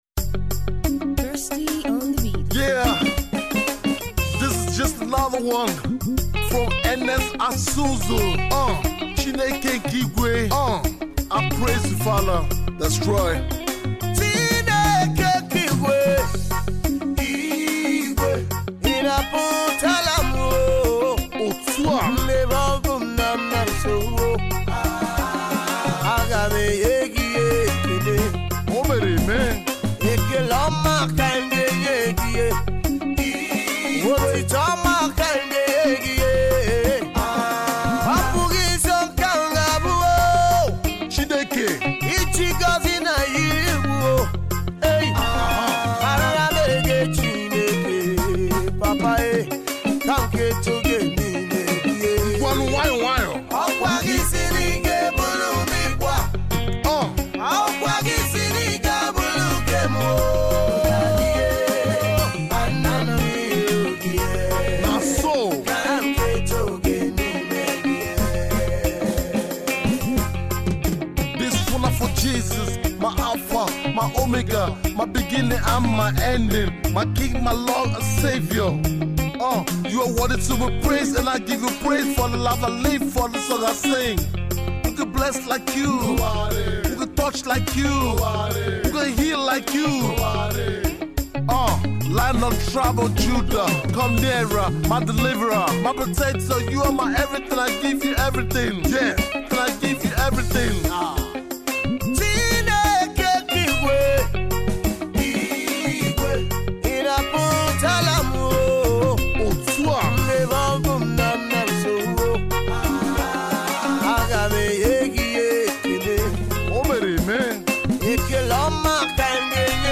a gospel track